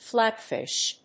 音節flát・fìsh 発音記号・読み方
/ˈflæˌtfɪʃ(米国英語)/